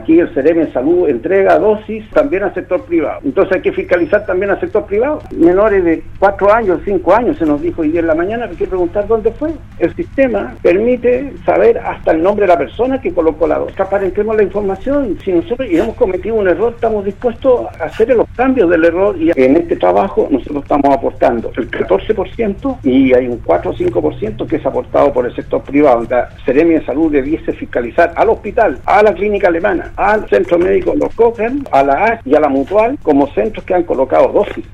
En conversación con el programa Haciendo Ciudad de Radio Sago